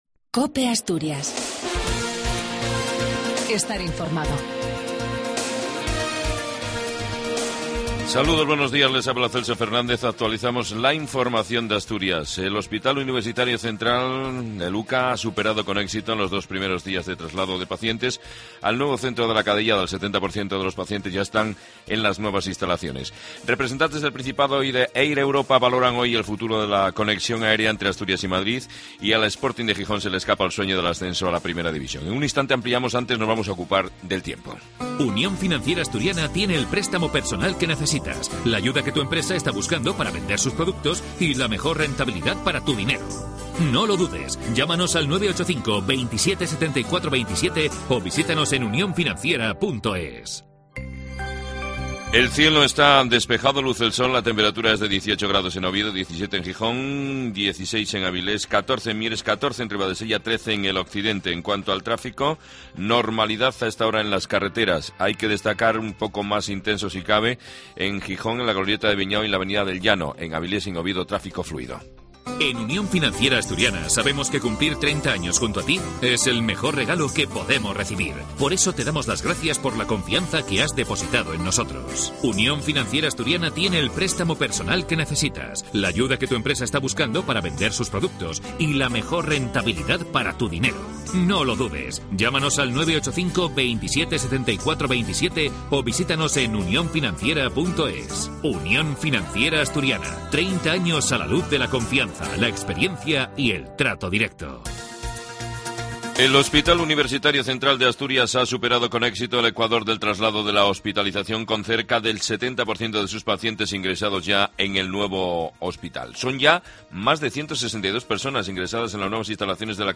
AUDIO: LAS NOTICIAS DE ASTURIAS A PRIMERA HORA DE LA MAÑANA.